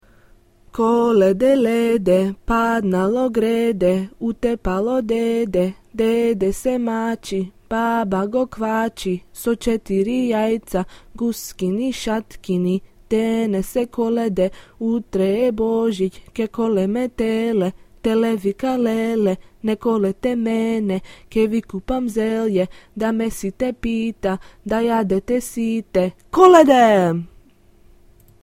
This rhyme is sang by children going door to door collecting nuts, fruit, candy, and coins on Christmas eve (similar to the Halloween tradition of trick or treating in the USA).